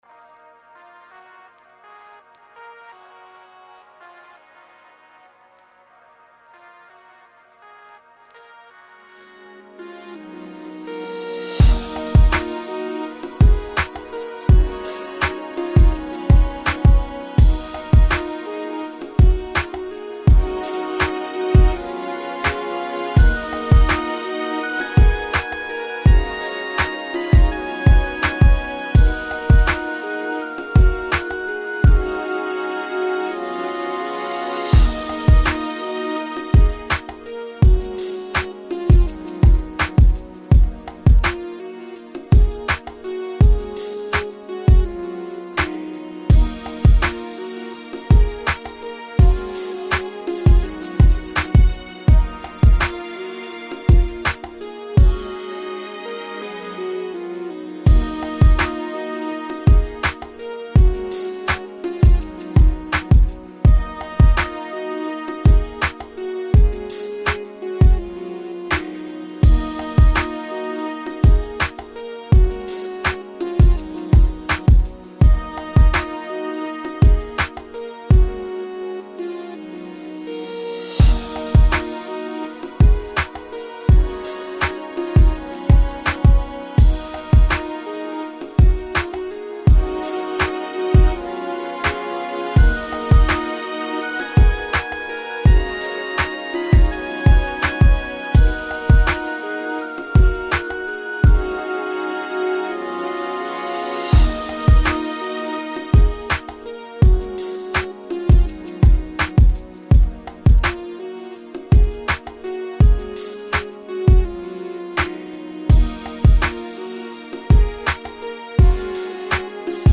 2006 Рэп Комментарии
молодая рэп группа